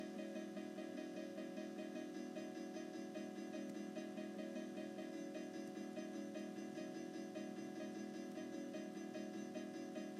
Noteblocks play sound inconsistently when several are repeatedly activated
The audio was recorded while standing still in the pictured setup. The high-pitched piano is the noteblock to the far right.
If what the high-pitched noteblock in the audio is doing isn’t clear, it is occasionally playing notes. Sometimes, it plays notes several times in a row, while other times, it seems to play every other or every third note.